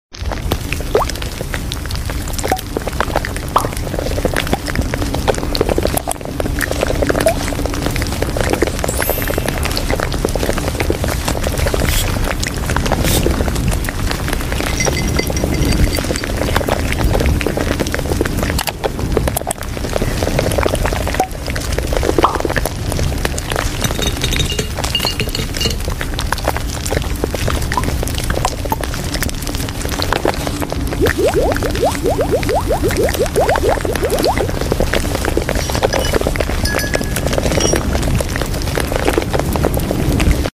. ༢ ࣪new asmr sound effects free download